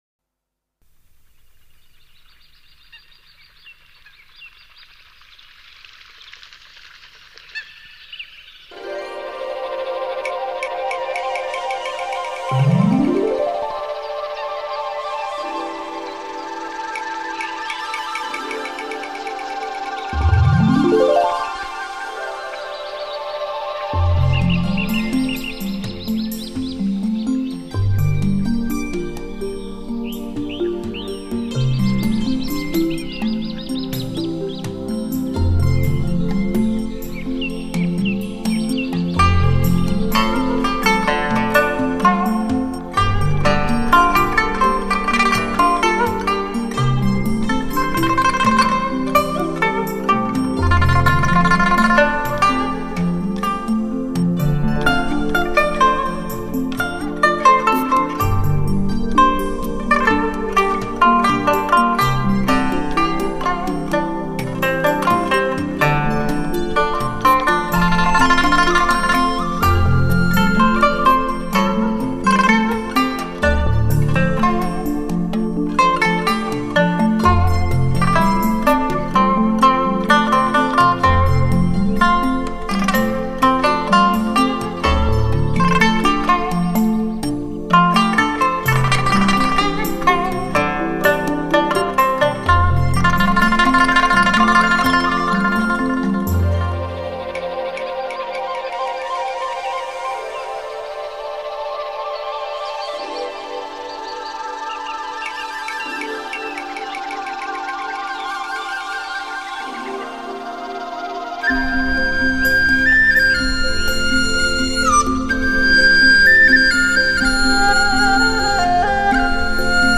精选6首新世纪东方极简禅味音乐